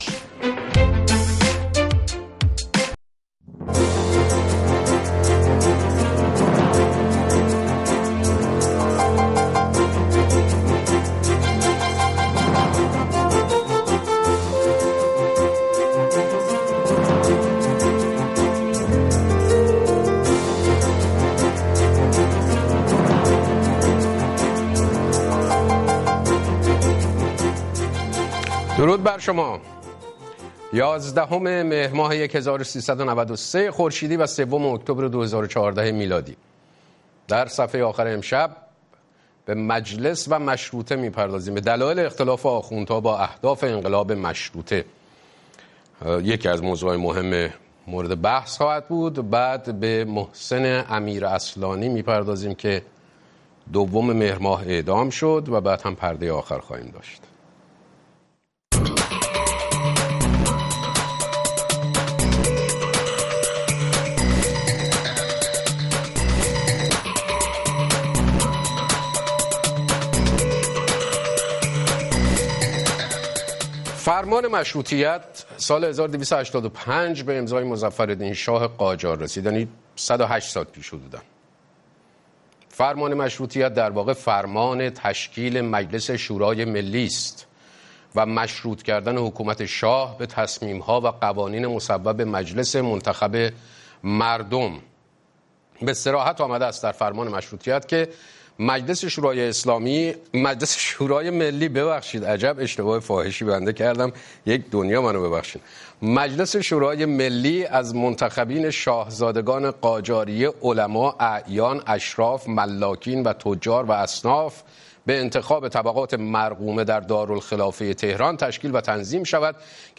مشروح خبرهای ايران و جهان، بحث و بررسيهای مهمترين رويدادهای خبری روز در گفتگو با خبرسازان، کارشناسان و تحليلگران